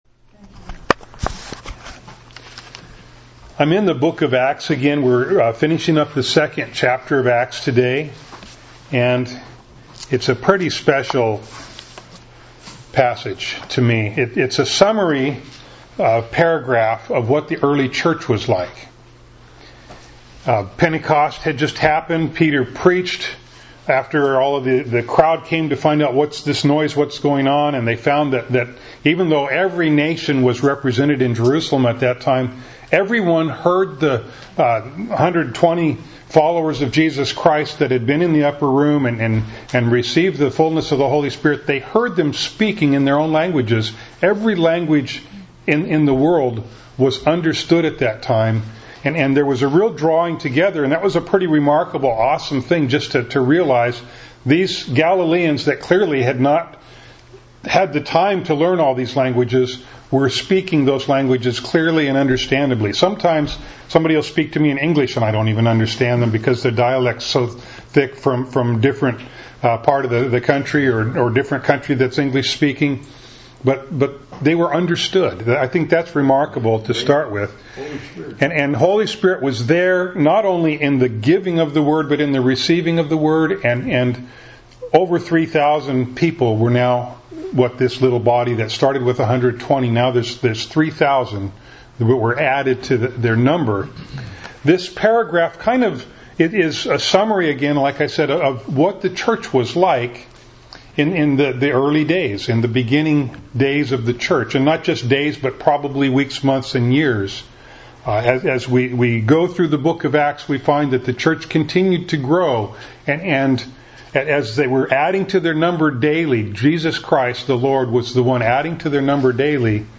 Acts 2:42-47 Service Type: Sunday Morning Bible Text